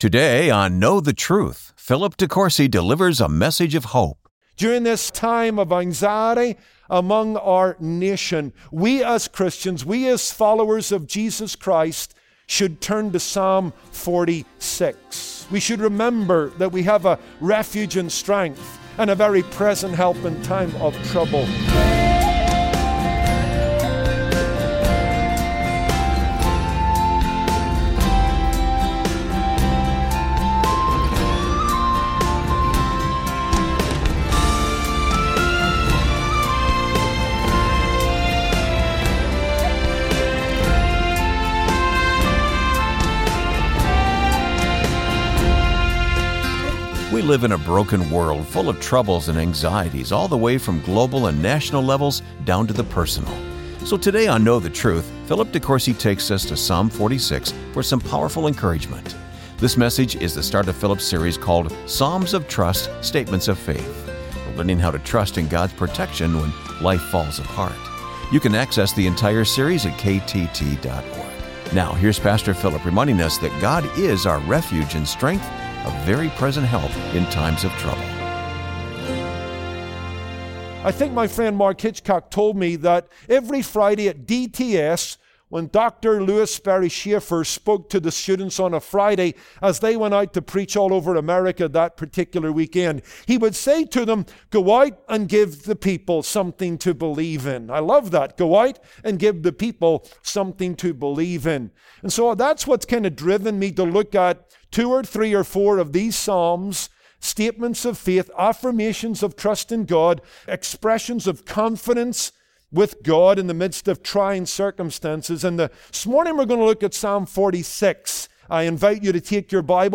We live in a broken world full of troubles and anxieties, all the way from global and national levels, down to the personal. So, on this Monday broadcast on KNOW THE TRUTH we begin a new short series drawing on selected Psalms titled Psalms of Trust; Statements of Faith.